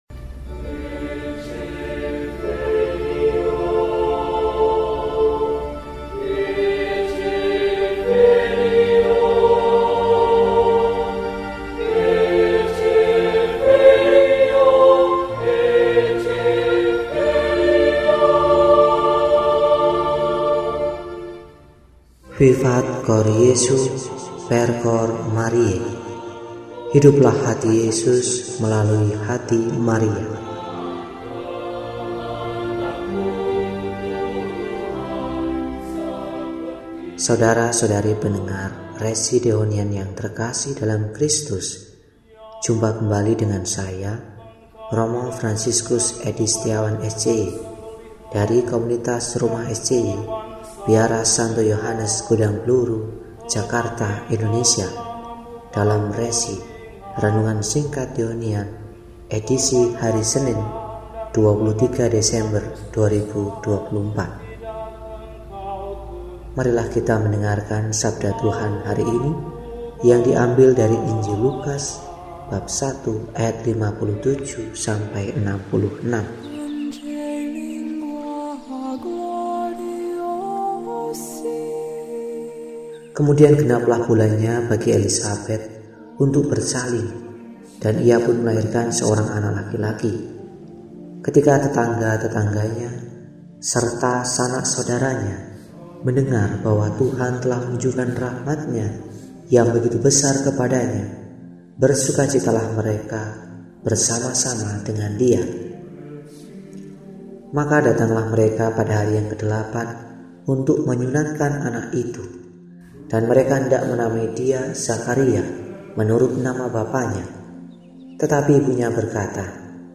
Senin, 23 Desember 2024 – Hari Biasa Khusus Adven – RESI (Renungan Singkat) DEHONIAN